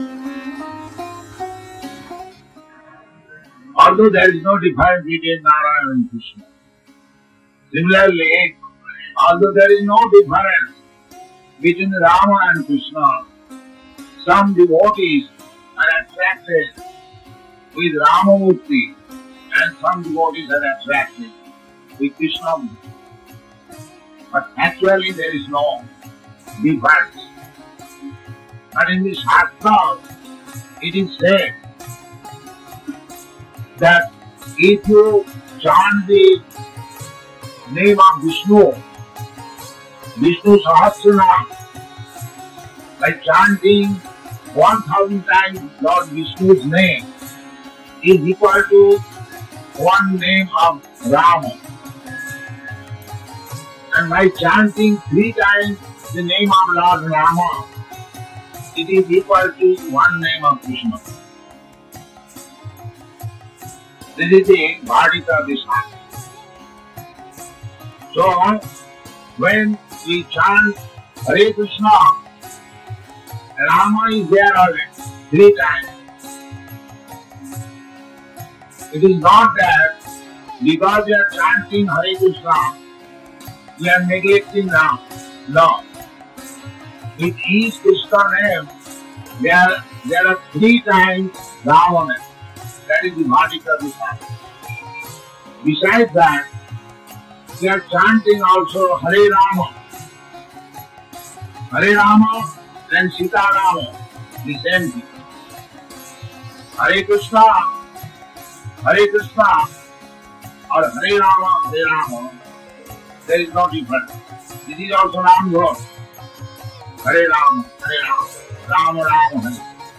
(740401 - Lecture BG 04.12 Appearance Day, Lord Ramacandra - Bombay)